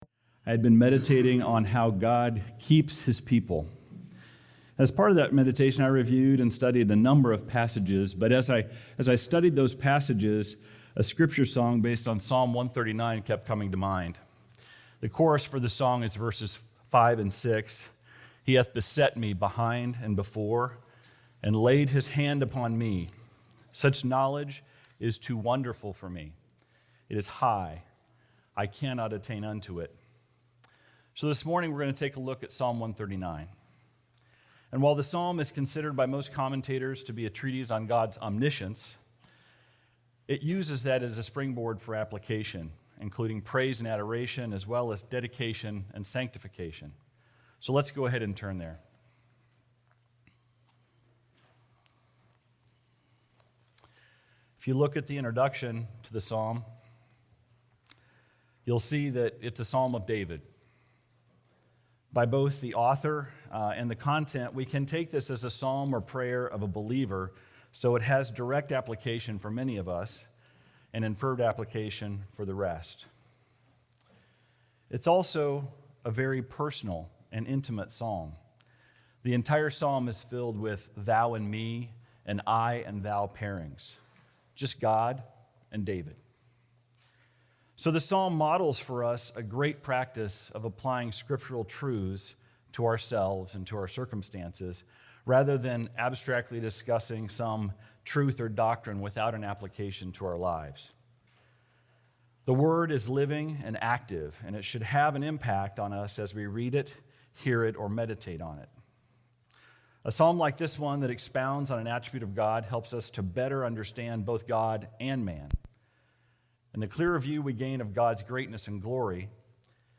How God Keeps His People | SermonAudio Broadcaster is Live View the Live Stream Share this sermon Disabled by adblocker Copy URL Copied!